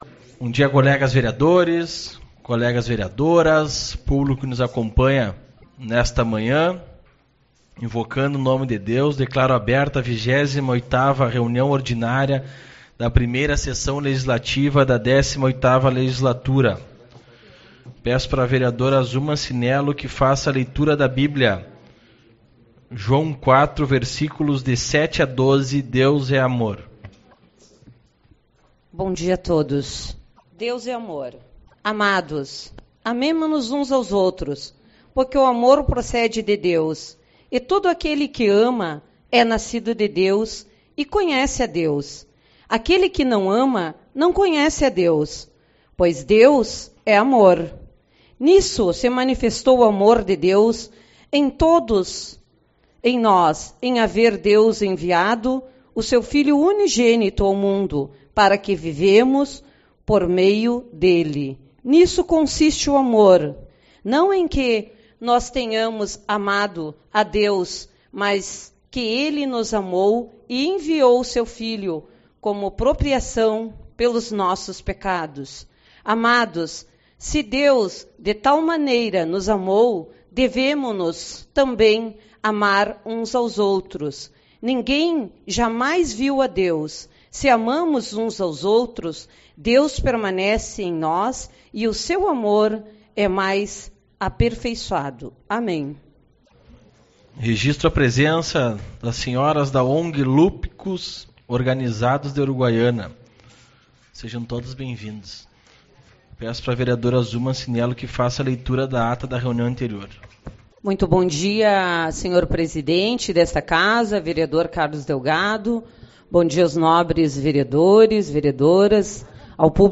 06/05 - Reunião Ordinária